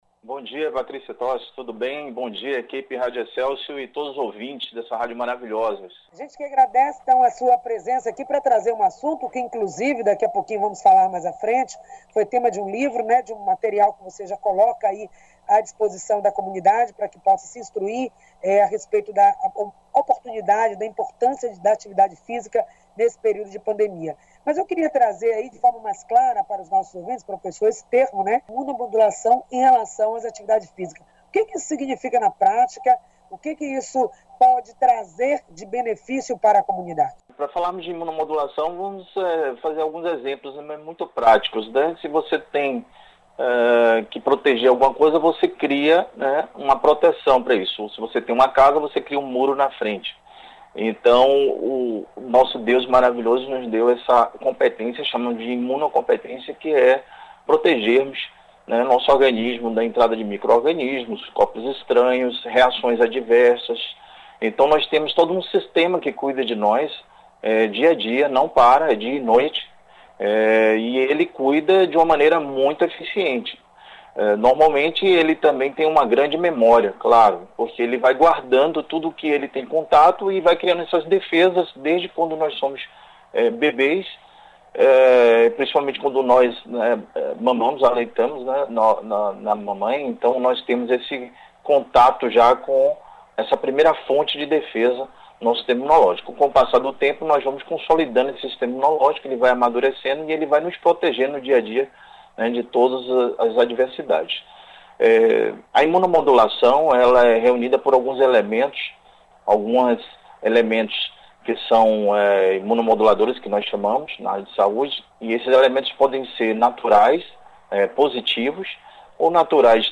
O Excelsior Saúde, acontece das 9 às 10h com transmissão pela Rádio Excelsior AM 840.